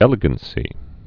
(ĕlĭ-gən-sē)